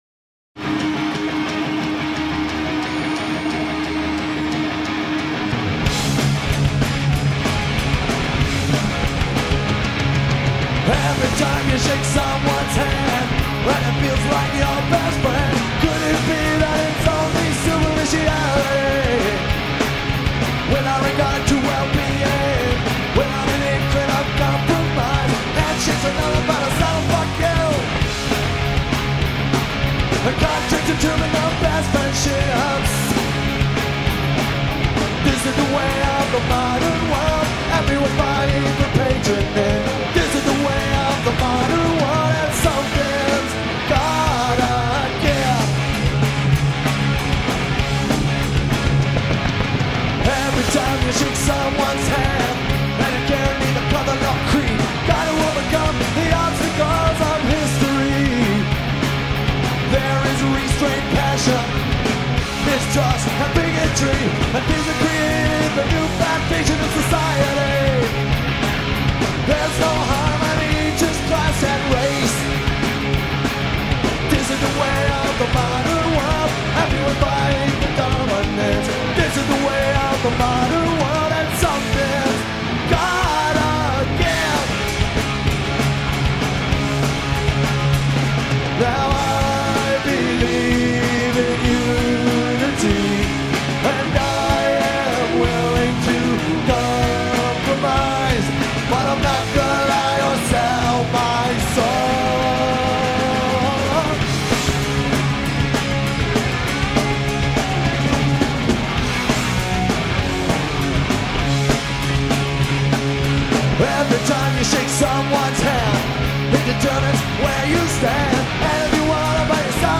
Tracks 2,3,6 and 7: live from KROQ Acoustic Xmas line